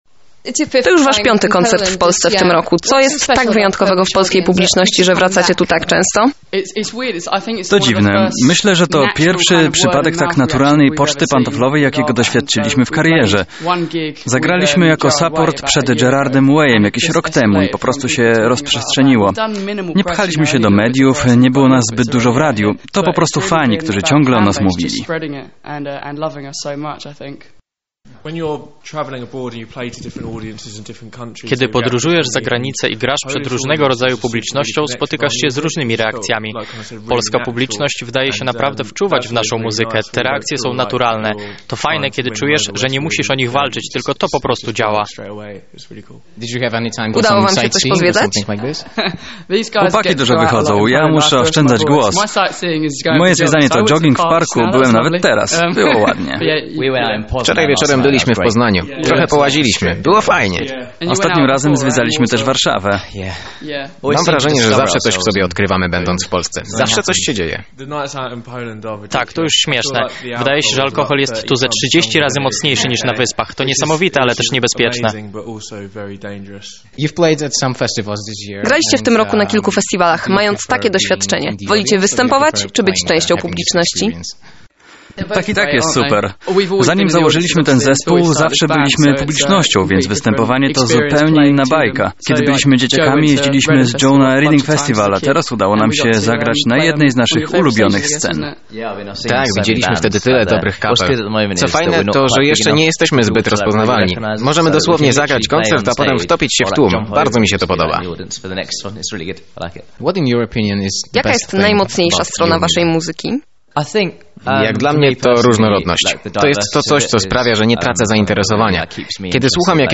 Wywiad z Nothing But Thieves
Wywiad-NBT-tlumaczony.mp3